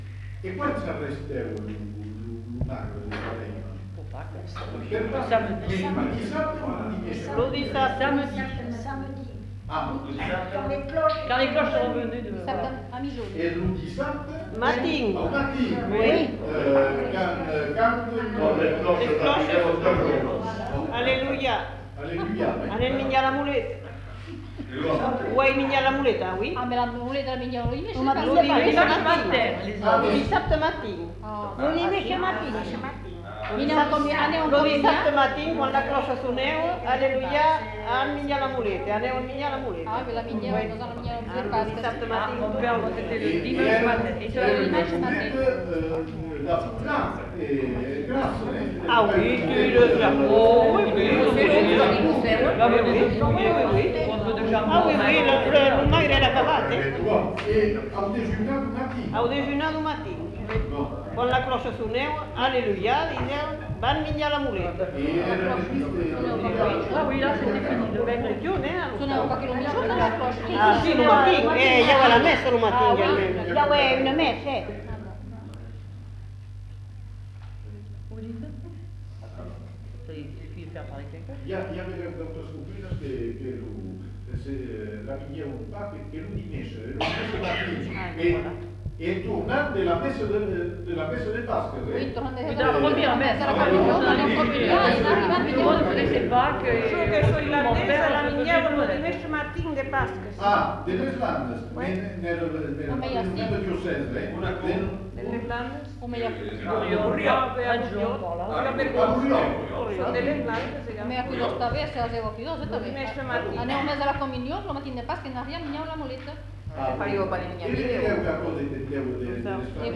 Lieu : Cudos
Genre : témoignage thématique